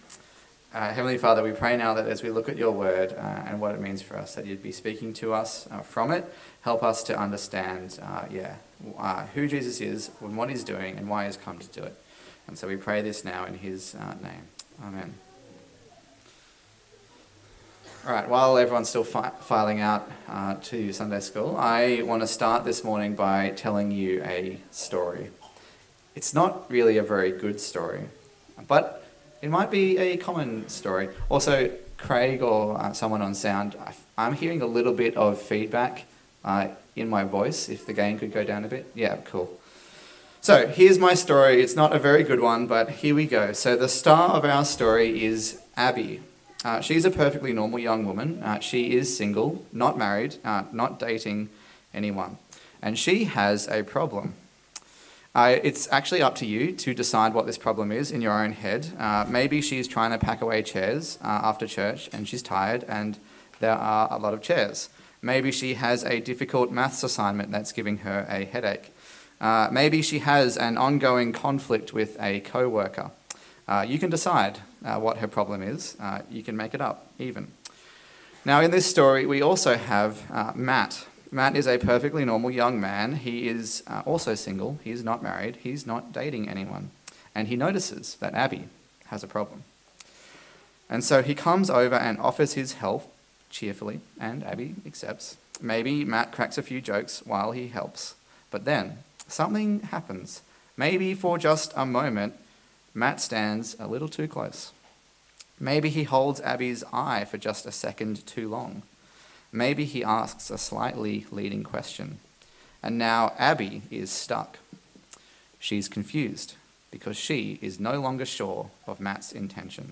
A sermon in the series on the Gospel of Luke
Service Type: Sunday Service